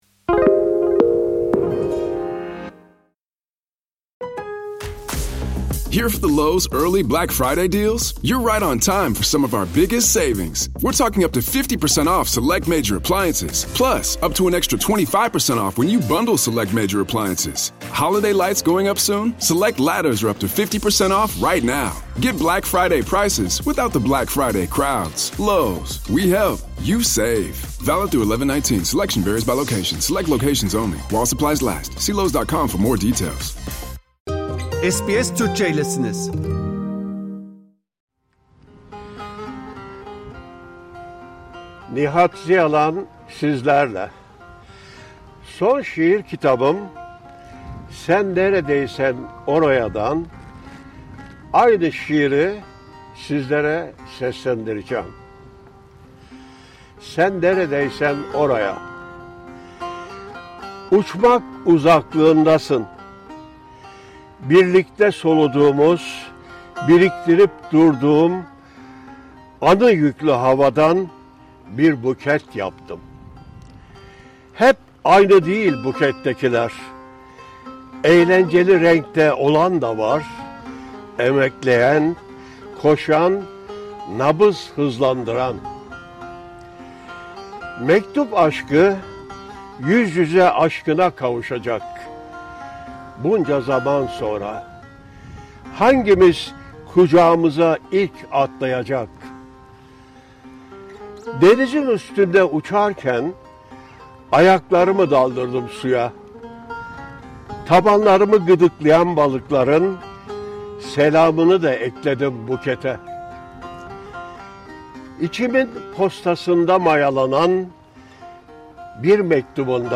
Bu söyleşide